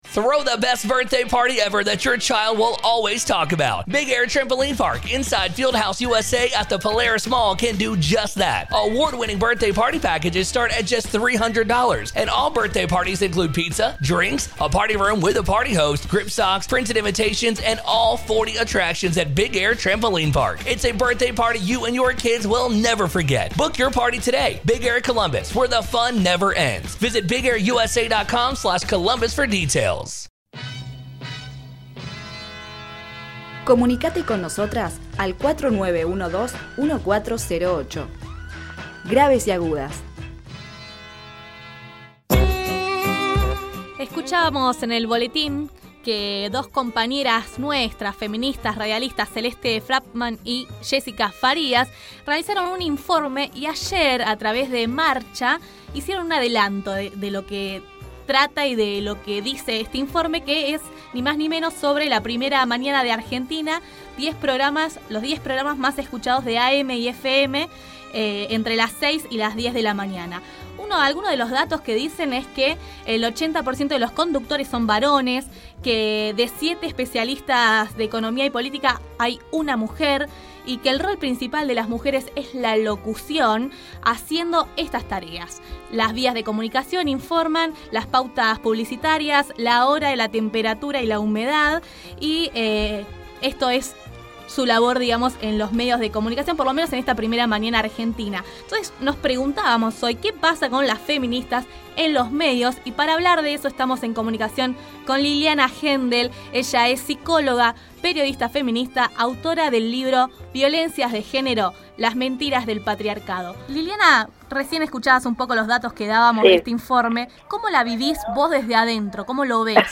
Enrevista